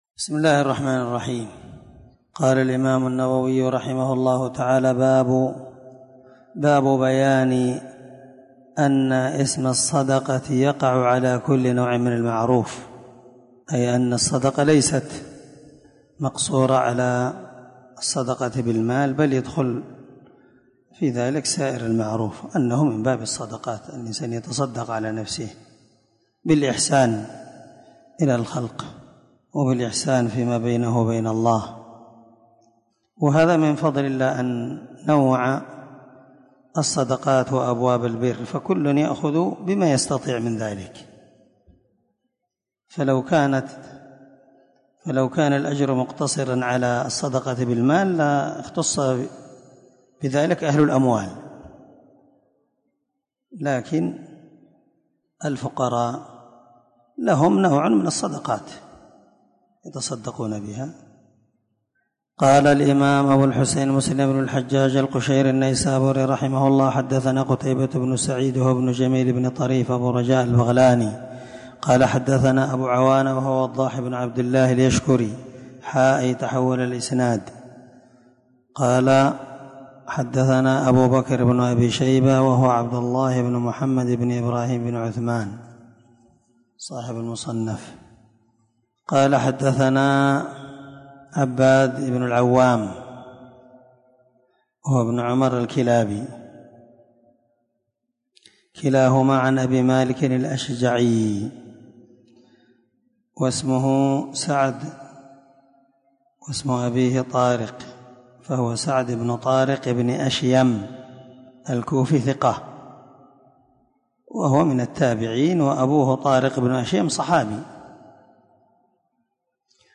دار الحديث- المَحاوِلة- الصبيحة